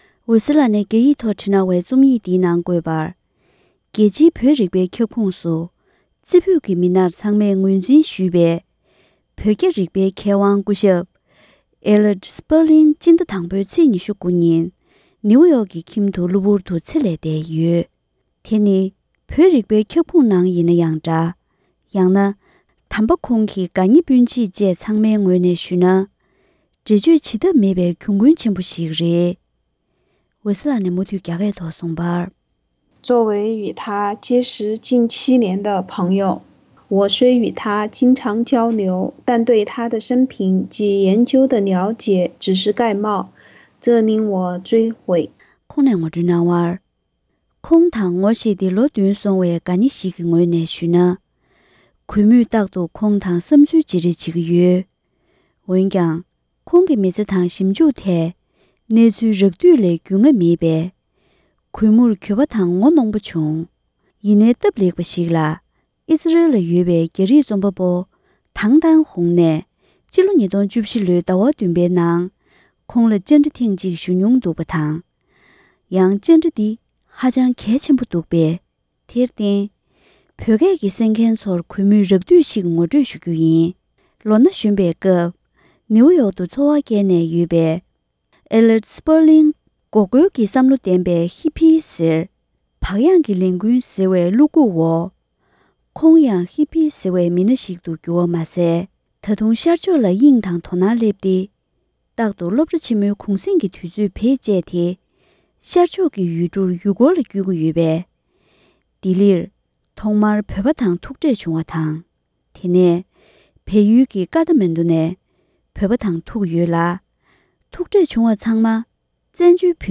བོད་རྒྱའི་མཁས་པ་སྐུ་ཞབས་Elliot Sperlingལ་བཅའ་འདྲི་ཞུས་པ། ལེ་ཚན་གཉིས་པ།